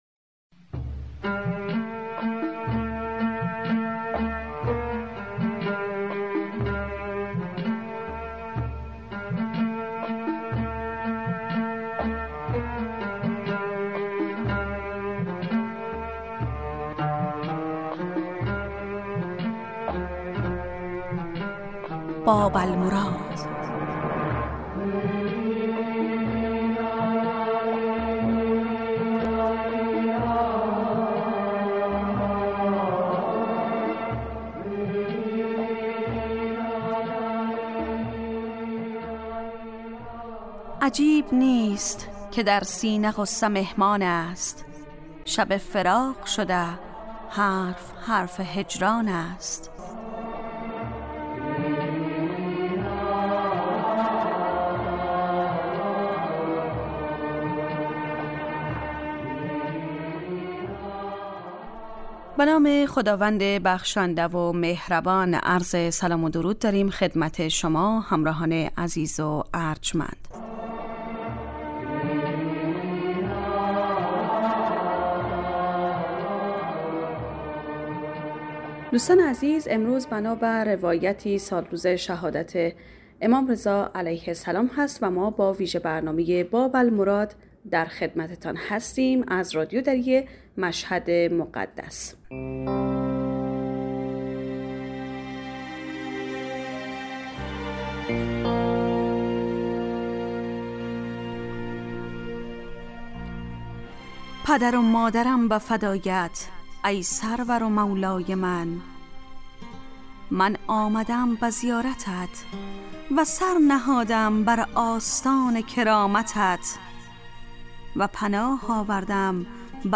آهنگهای درخواستی